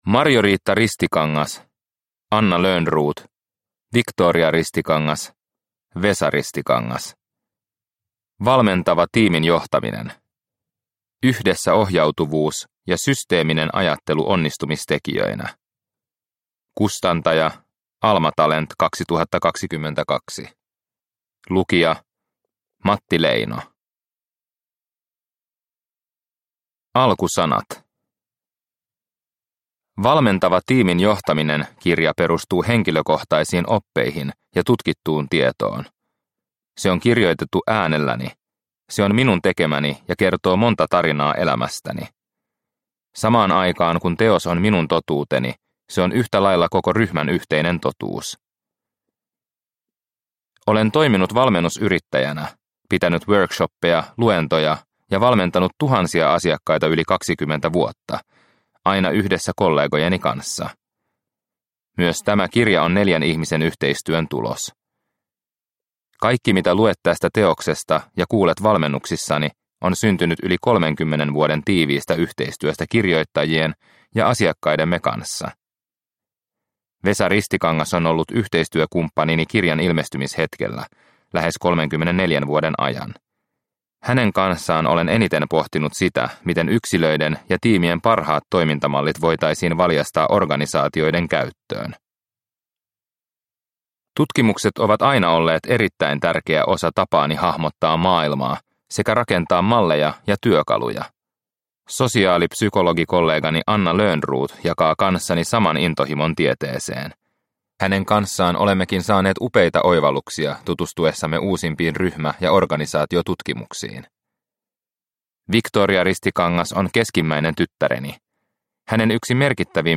Valmentava tiimin johtaminen – Ljudbok – Laddas ner